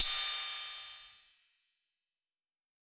6RIDE 1.wav